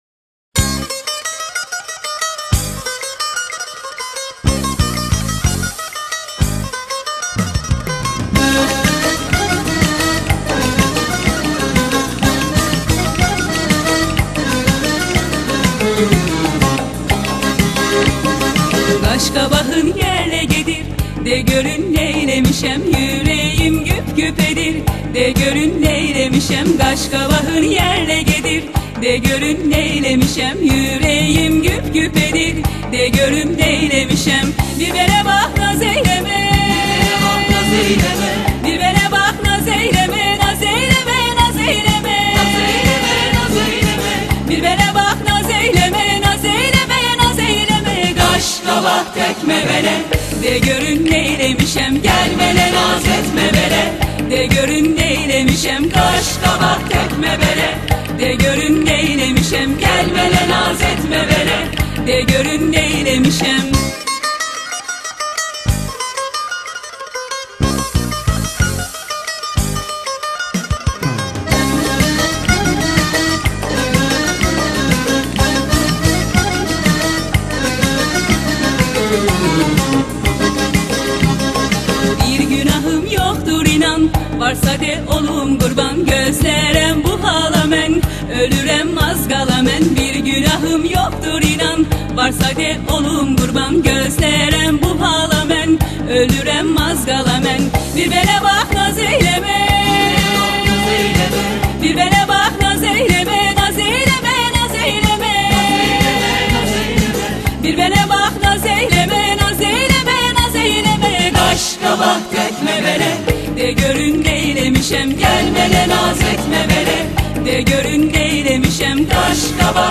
Azerbeycan türküsü